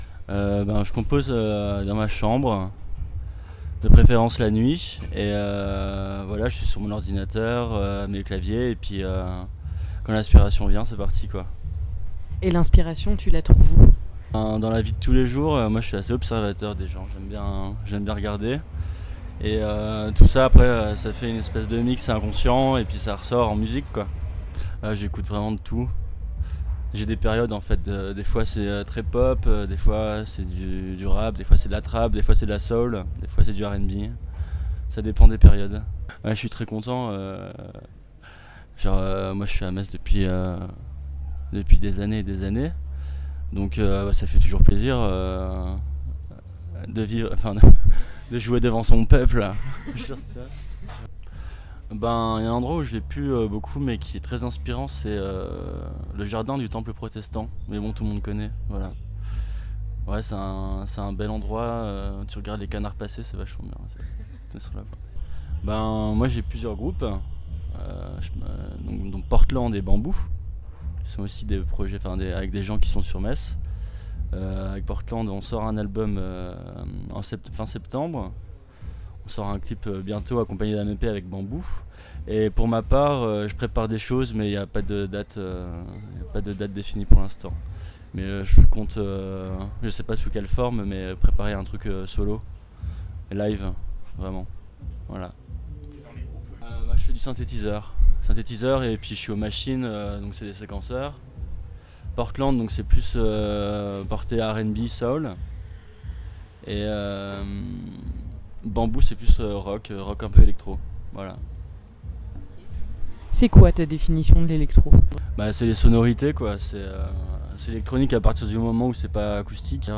Dans le cadre du festival Ondes Messines à Metz, nous avons pu rencontrer quelques uns des artistes présents pour le concert place de la République le 29 juin dernier.
Retour en interviews sur cet évènement qui ouvre notamment le festival Constellations.